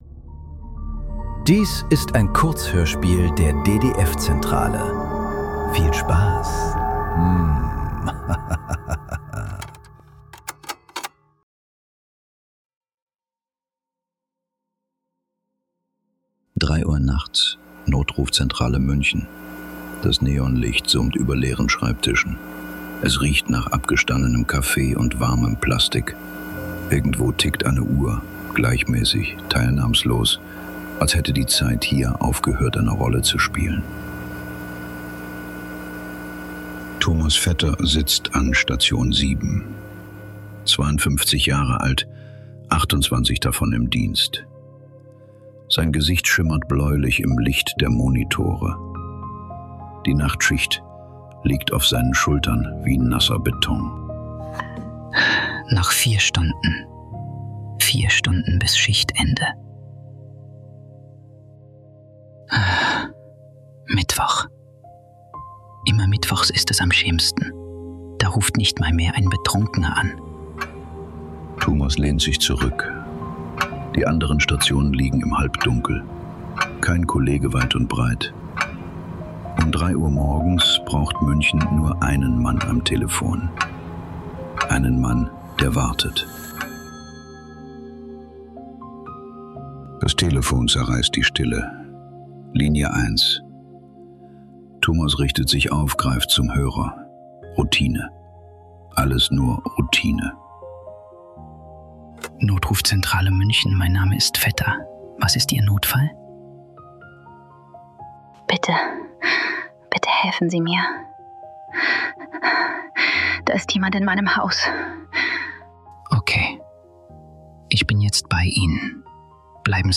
Der letzte Anruf ~ Nachklang. Kurzhörspiele. Leise.